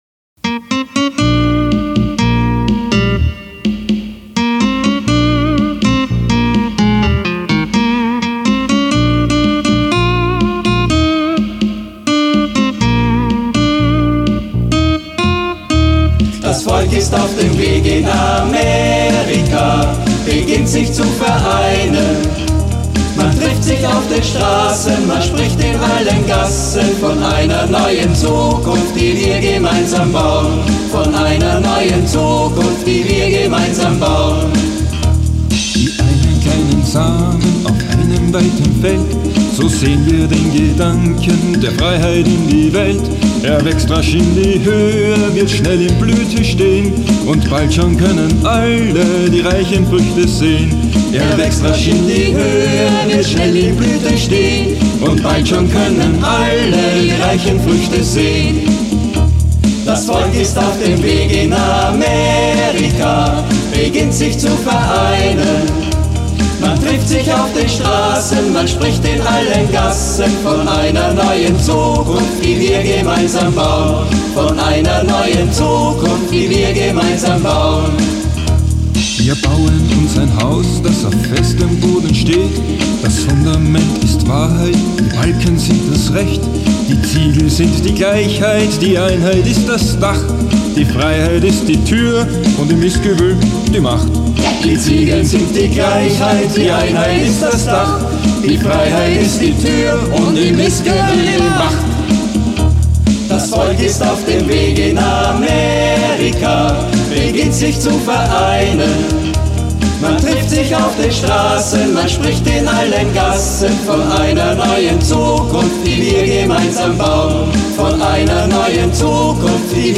13 Lieder brasilianischer Basisgemeinden wurden übersetzt